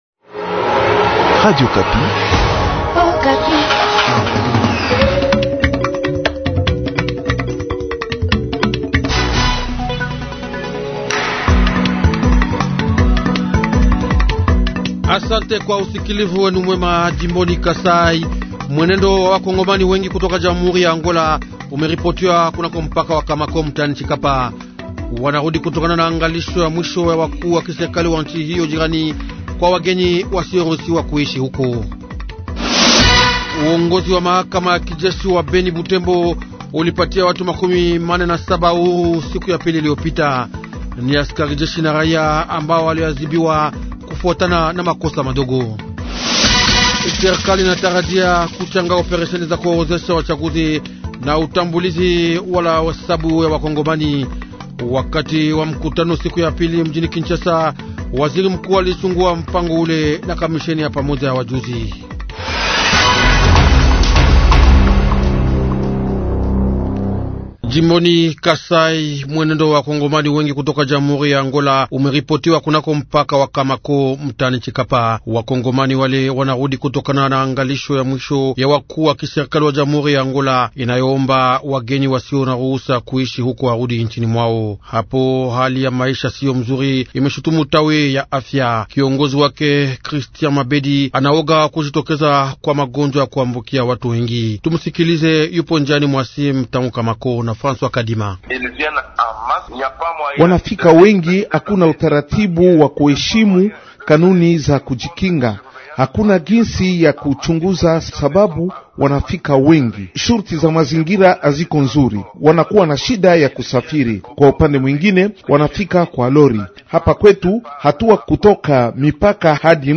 Journal Swahili Matin Jeudi 09/09/2021